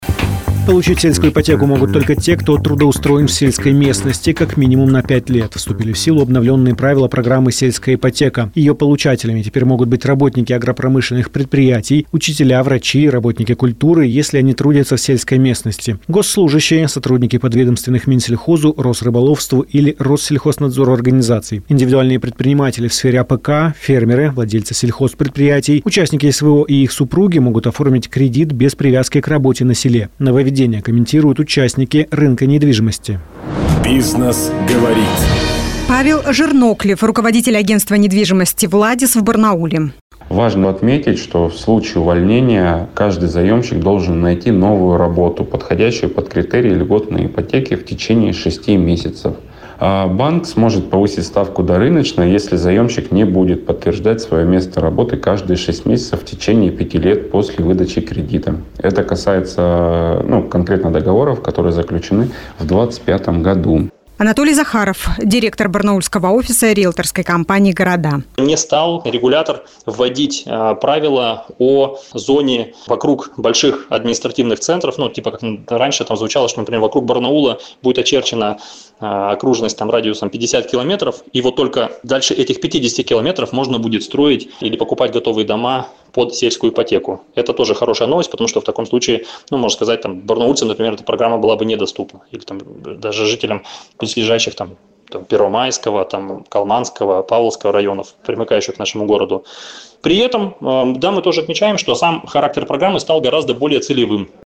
В эфире радиостанции Business FM (Бизнес ФМ) Барнаул участники рынка недвижимости прокомментировали нововведения.